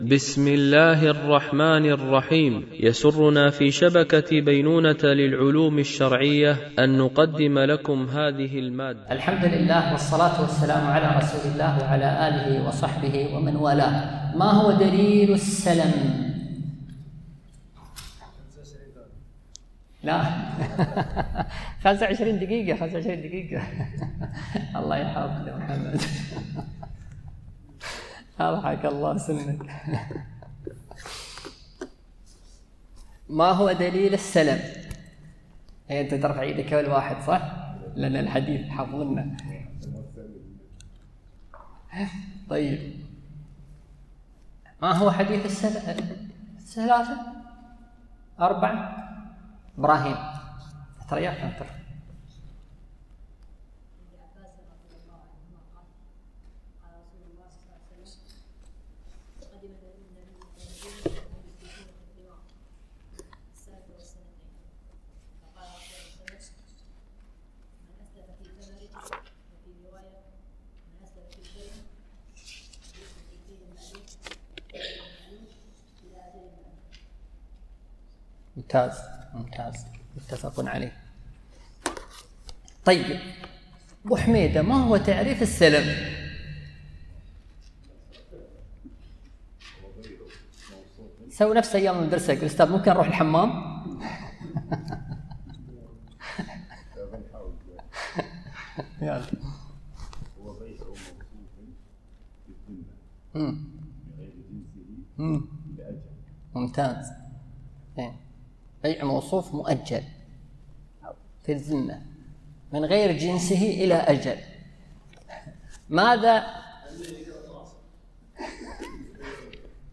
شرح الفقه المالكي ( تدريب السالك إلى أقرب المسالك) - الدرس 52 ( كتاب البيوع )